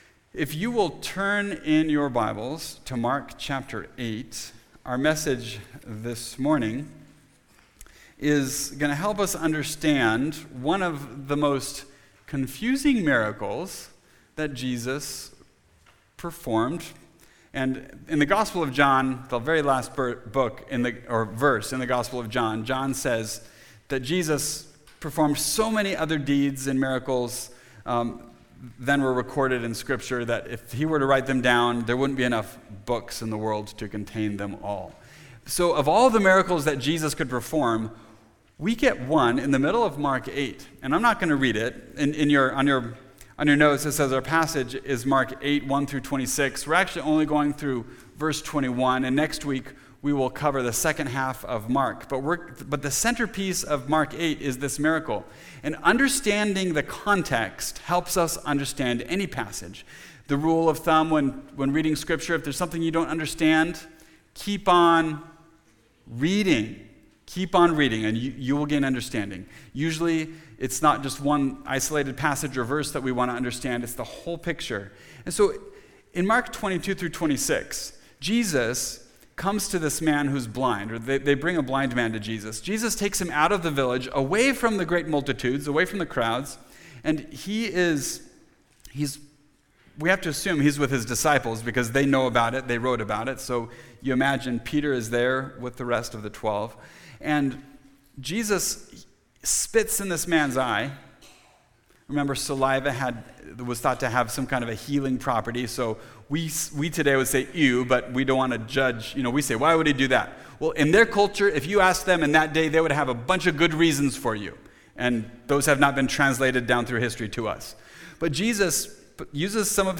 Spiritual Sight – Part 1 (Mark 8:1-26) – Mountain View Baptist Church